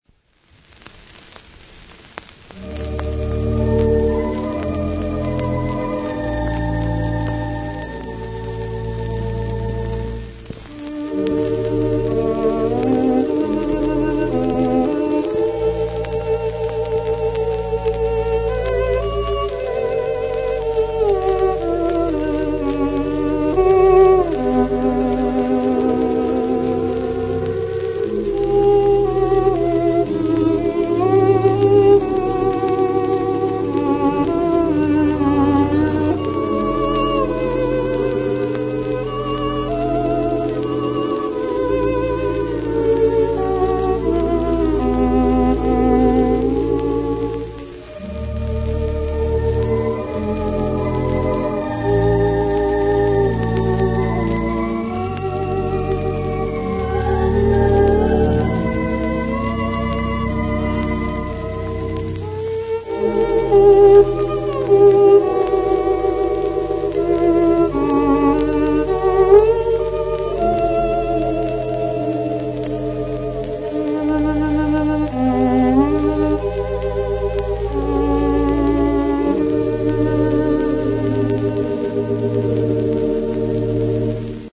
Part of our continuing effort to bring back our 2/7 Mighty WurliTzer at the Skandia Theatre is to find all known documentation.
Skandia_2 was recorded in the mid forties. I think the organ and the violin mix quite well.
Note that I have done no processing of these recordings, so there is some "frying tonight" background noise in them.